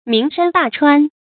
名山大川 注音： ㄇㄧㄥˊ ㄕㄢ ㄉㄚˋ ㄔㄨㄢ 讀音讀法： 意思解釋： 著名的高山和大河。